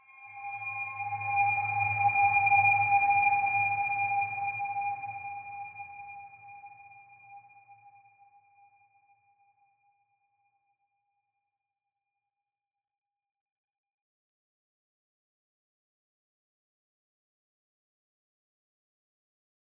sonarAmbienceShuttle2.ogg